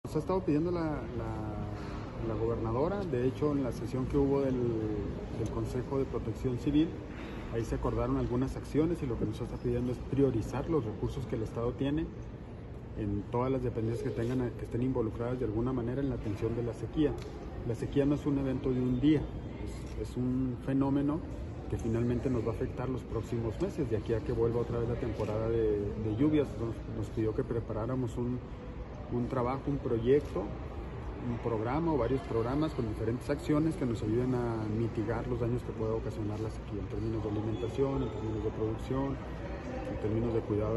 AUDIO: JOSÉ DE JESÚS GRANILLO, SECRETARÍA DE HACIENDA ESTATAL